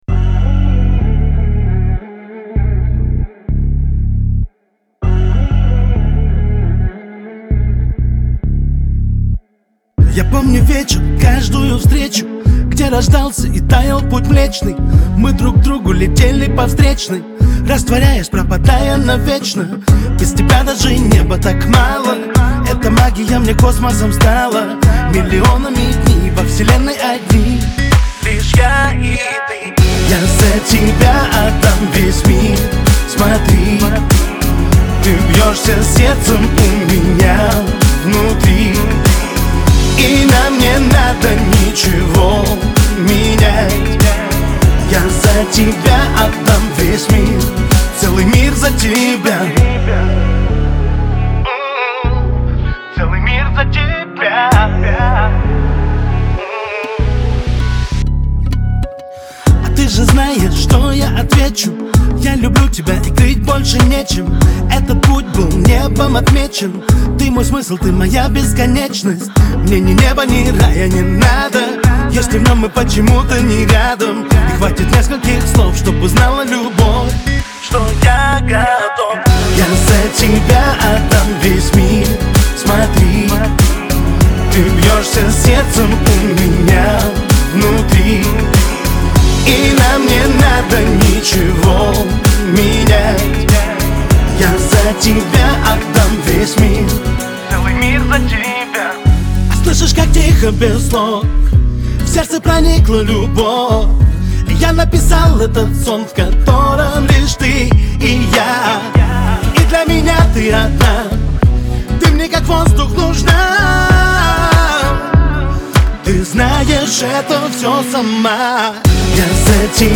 это романтичная и мелодичная песня в жанре поп